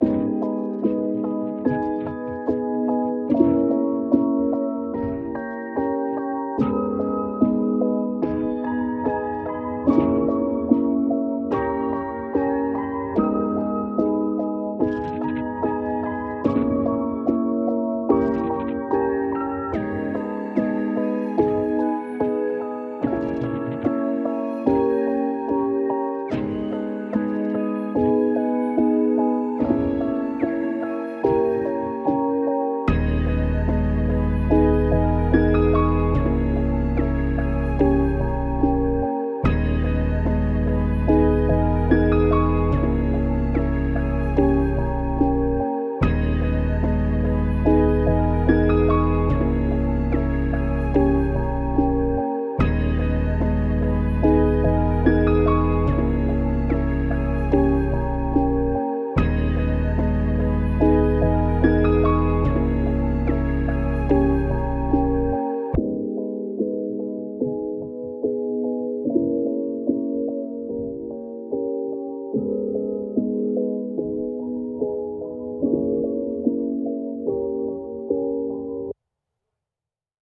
n.a.h.w.c. 146bpm.wav